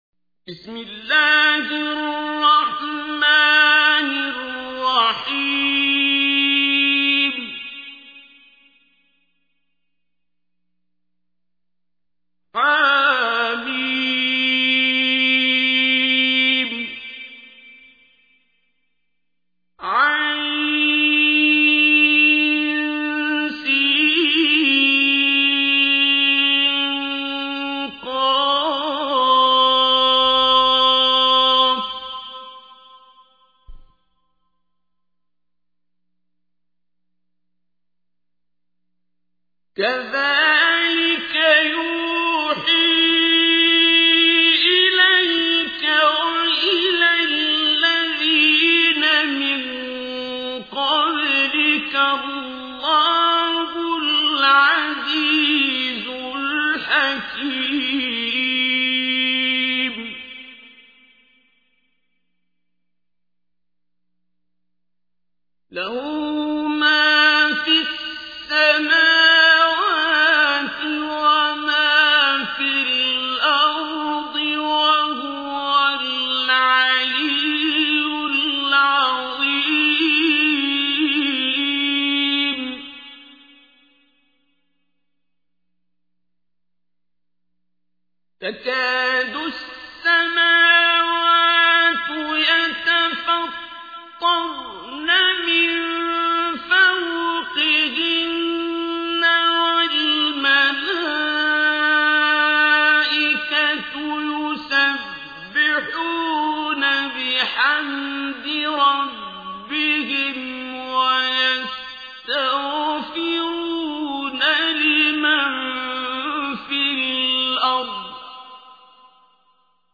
تحميل : 42. سورة الشورى / القارئ عبد الباسط عبد الصمد / القرآن الكريم / موقع يا حسين